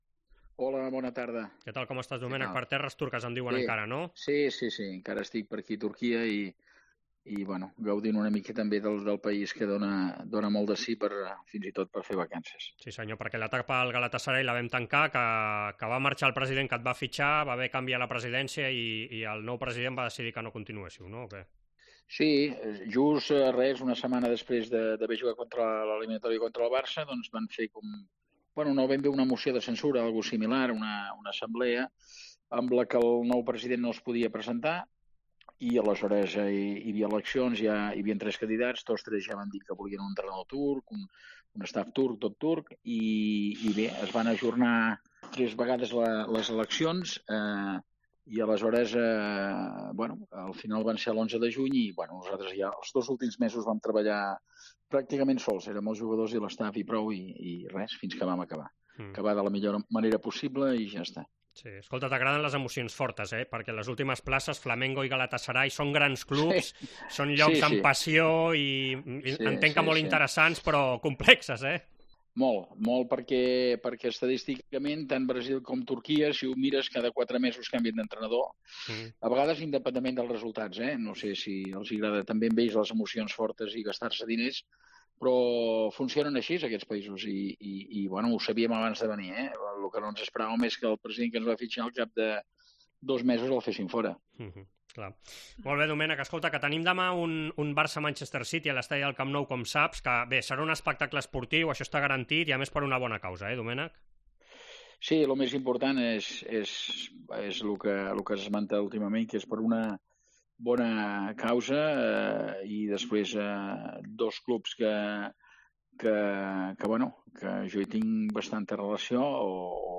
El técnico catalán Domènec Torrent, exayudante de Pep Guardiola en el Bayern y el City, ha pasado por los micrófonos de Esports COPE antes del partido que este miércoles enfrentará al Barça y el City en el Camp Nou, amistoso para recaudar fondos para la investigación del ELA, con Juan Carlos Unzué como principal embajador.